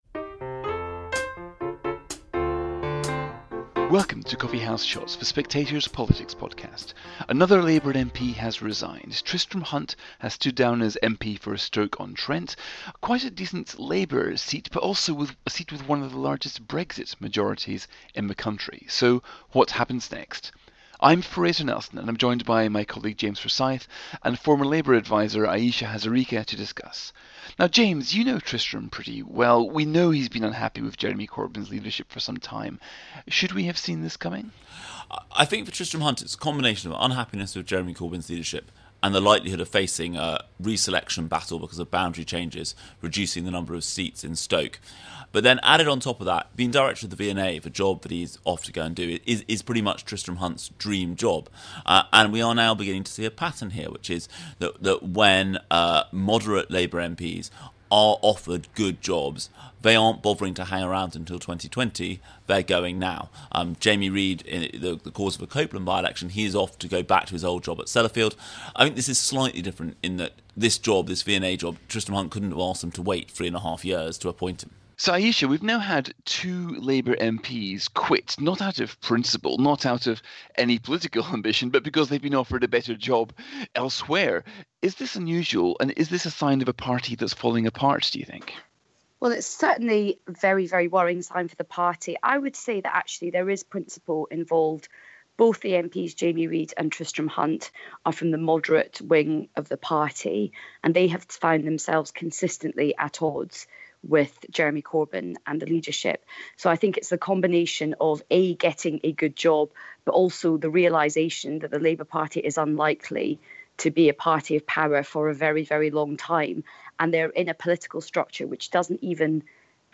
Presented by Fraser Nelson.